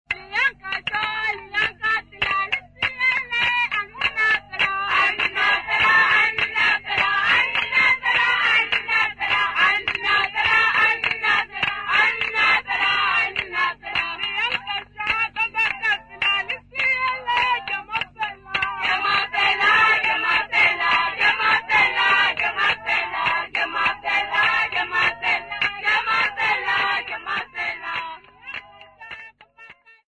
Sesotho people
Folk music Africa
Sacred music South Africa
Church music South Africa
Field recordings South Africa
Africa South Africa Ficksburg, Free State Province sa
Unaccompanied traditional Sesotho song